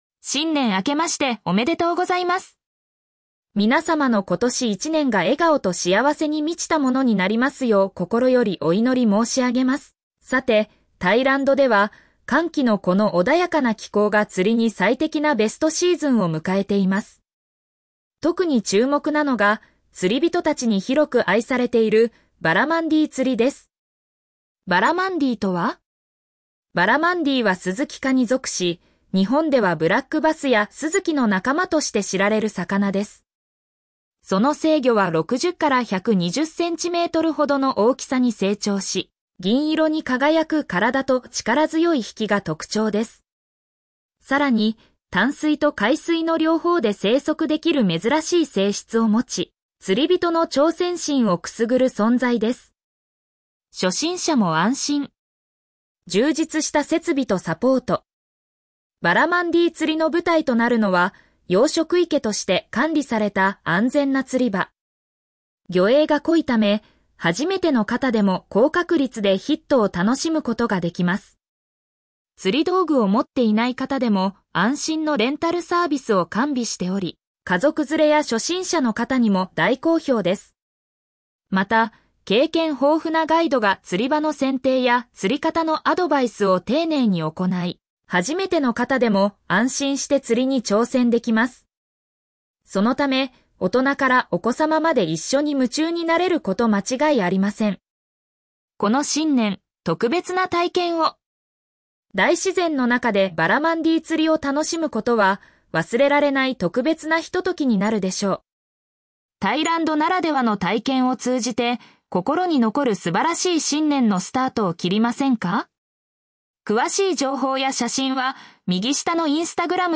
読み上げ